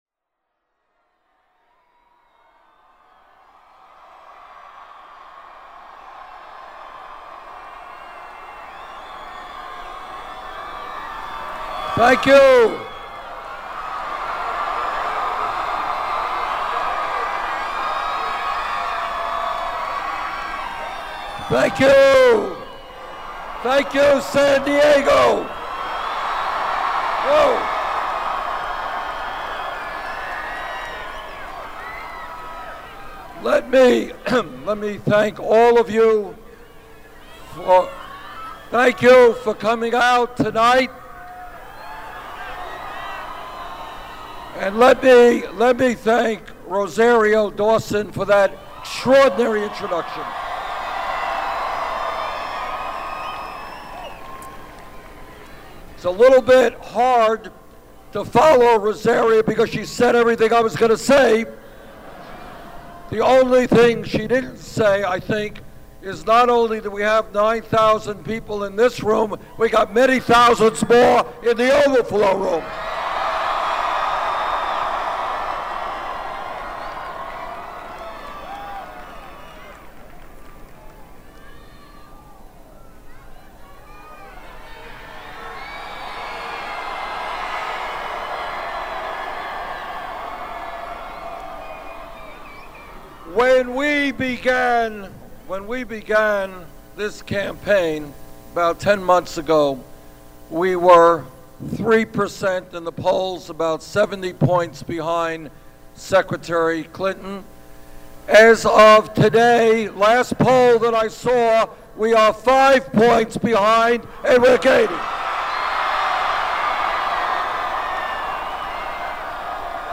In his opening remarks of a speech interrupted by frequent enthusiastic applause, Sanders took aim at voter suppression tactics on a day when long lines at minority polling places and other questionable tactics drew strong criticism in Arizona.
Audio: Bernie Sanders speech in San Diego 3-22-16 Download : Bernie Sanders speech in San Diego 3-22-16